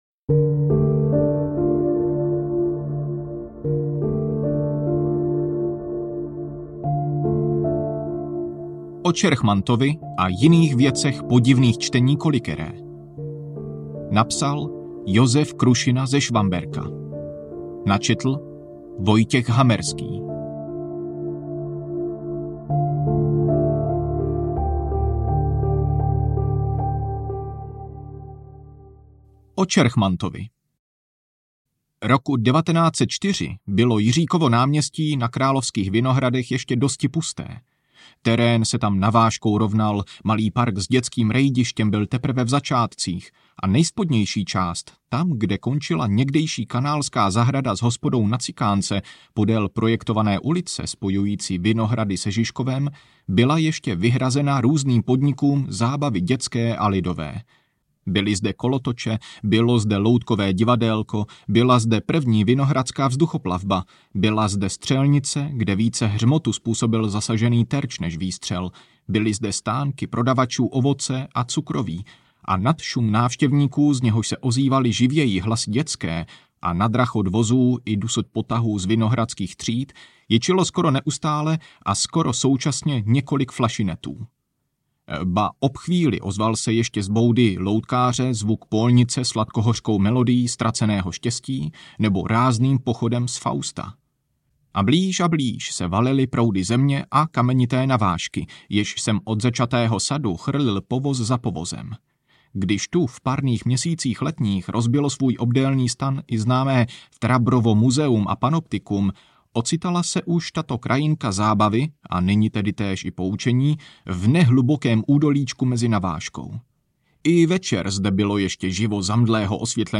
Audio knihaO Čerchmantovi a jiných věcech podivných čtení kolikeré
Ukázka z knihy